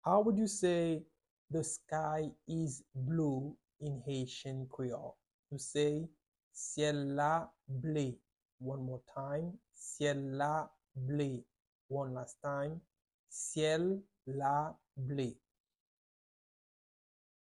Pronunciation:
Listen to and watch “syèl la ble” audio pronunciation in Haitian Creole by a native Haitian  in the video below: